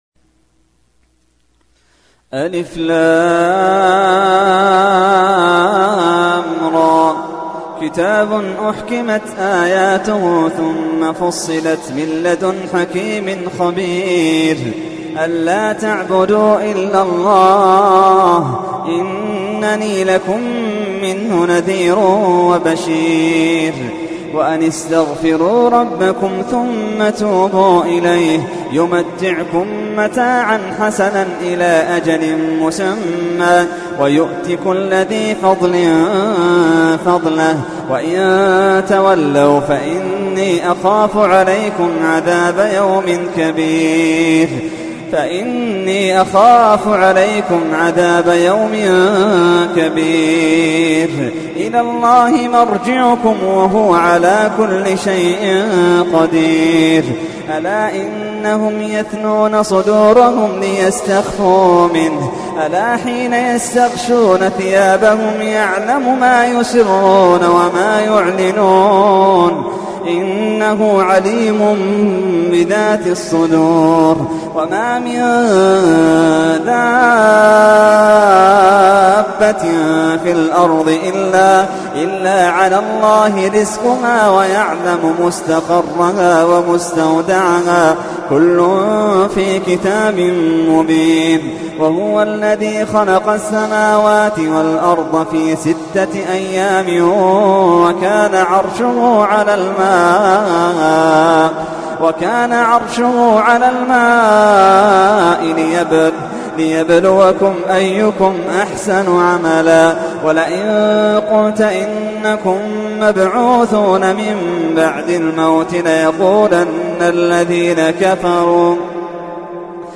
تحميل : 11. سورة هود / القارئ محمد اللحيدان / القرآن الكريم / موقع يا حسين